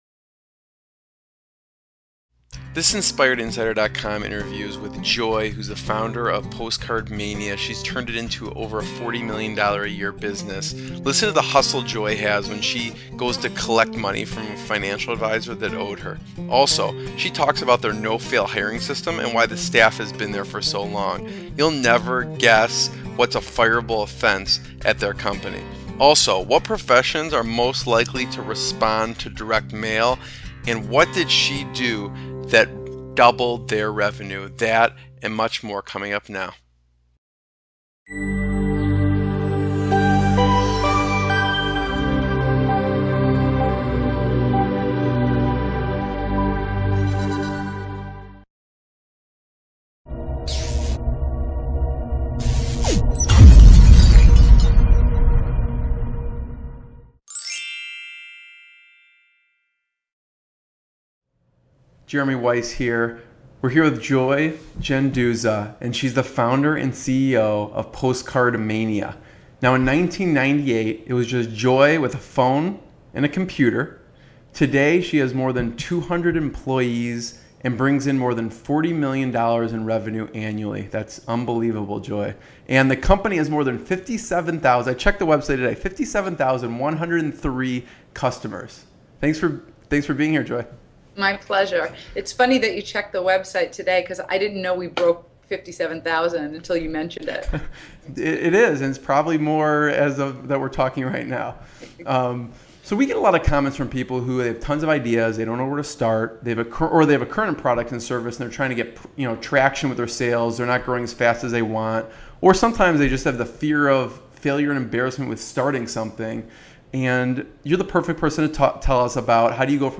Watch this interview to see: [Video & mp3 included] -What did she do that doubled their revenue? -How does her no fail hiring system work?